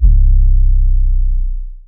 808 (Stay in Low).wav